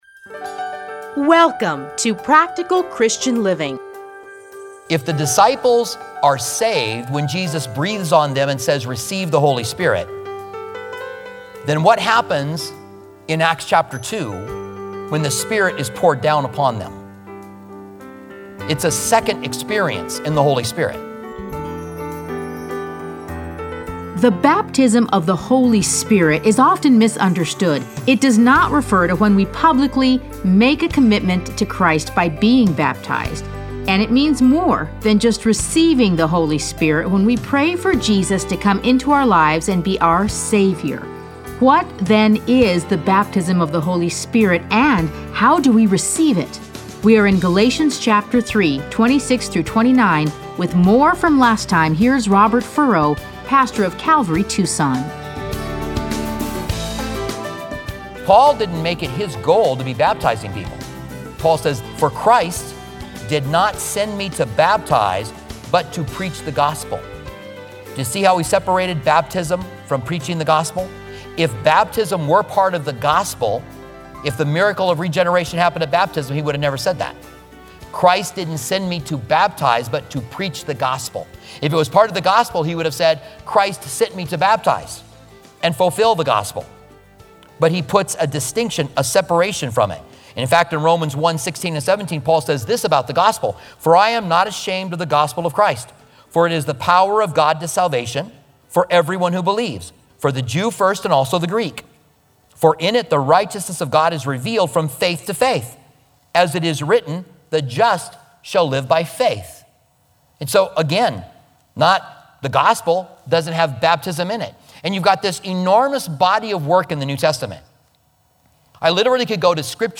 Listen to a teaching from Galatians 3:26-29.